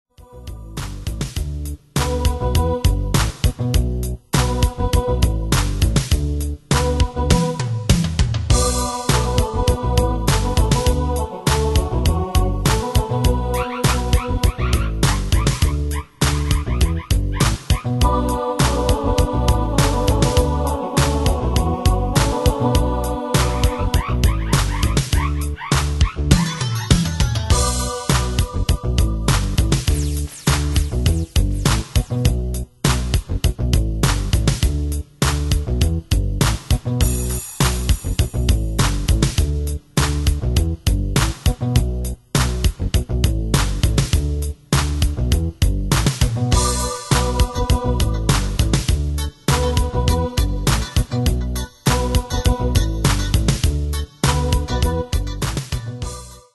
Style: PopAnglo Ane/Year: 1995 Tempo: 101 Durée/Time: 4.19
Danse/Dance: PopRock Cat Id.
Pro Backing Tracks